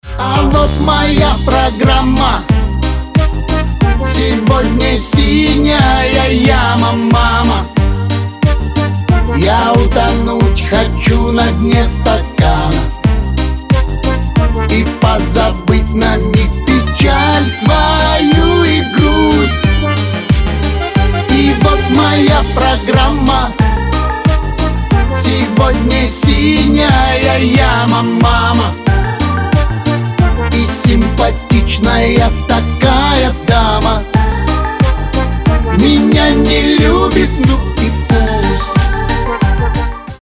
душевные
грустные
застольные